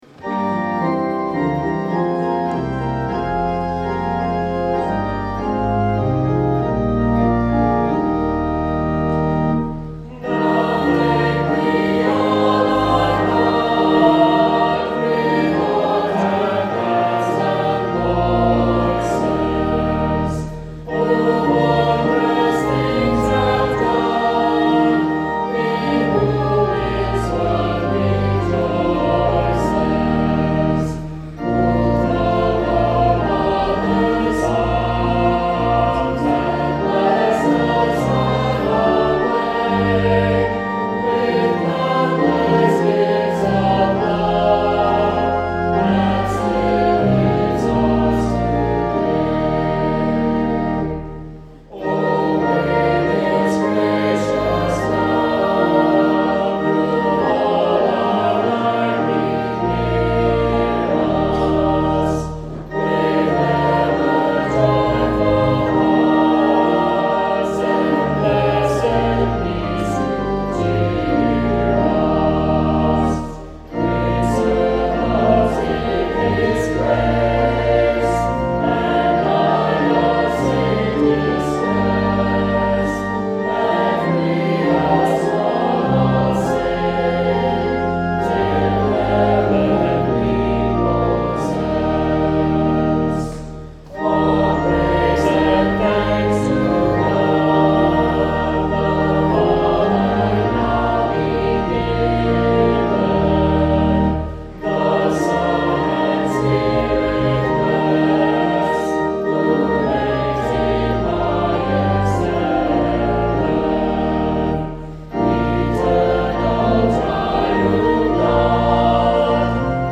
Text by Martin Rinkart; Music by Johann Cruger; Adapted by Felix Mendelssohn
Saint Clement Choir Sang this Song